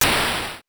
8 bits Elements
explosion_3.wav